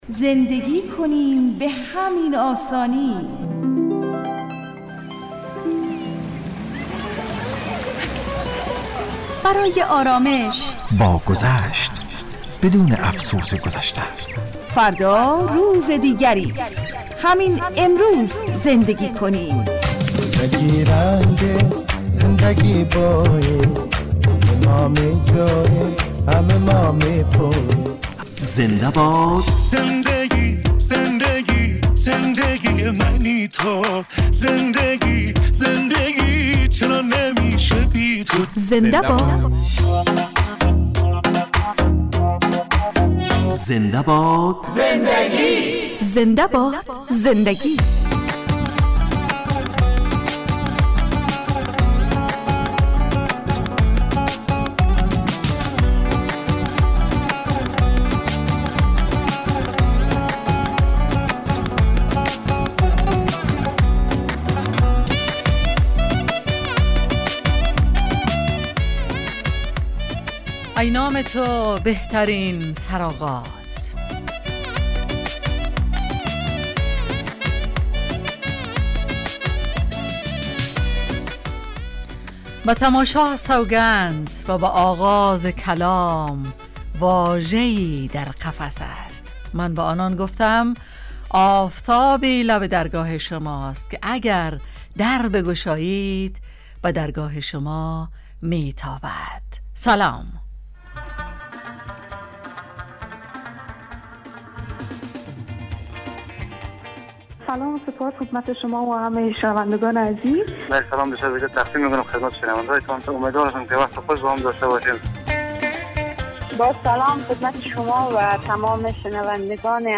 زنده باد زندگی(گپ و سخن)